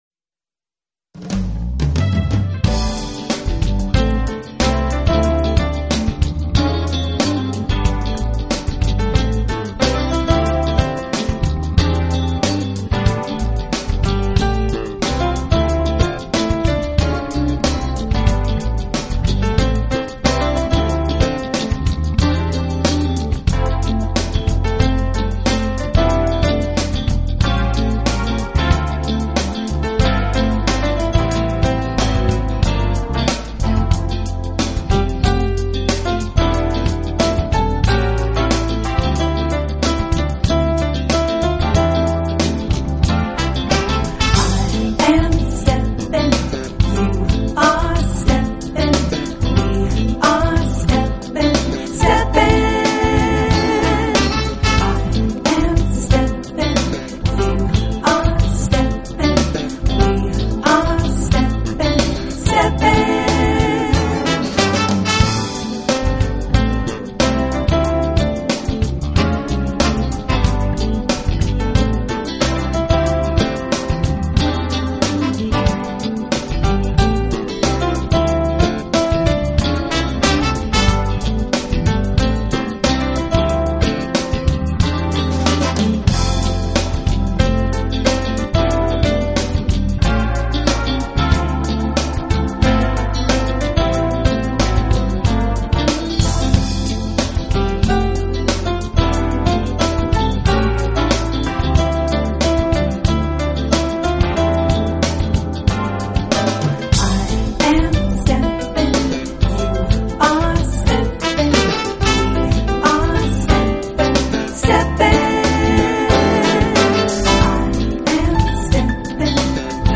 Genre: Smooth Jazz(Piano)